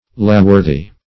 laughworthy - definition of laughworthy - synonyms, pronunciation, spelling from Free Dictionary
Laughworthy \Laugh"wor`thy\, a.